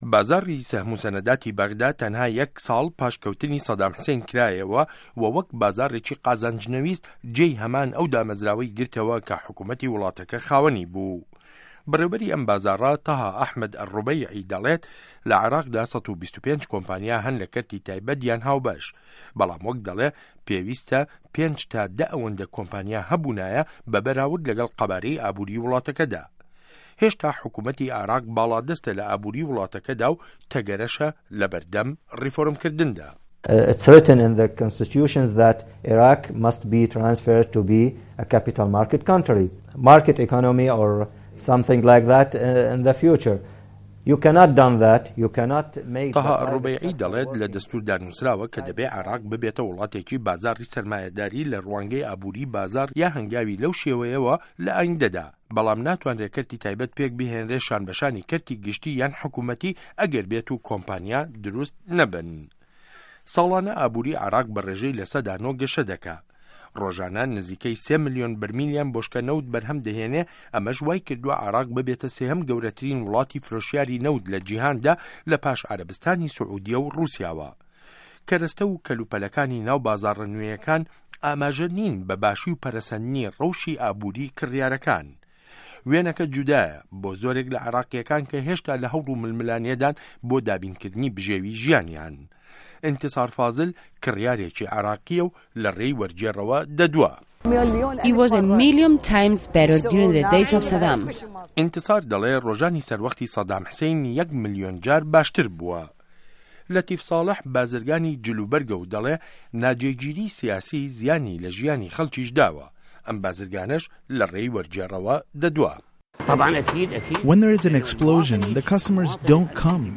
ڕاپۆرتی ئابوری عێراق و گه‌نده‌ڵی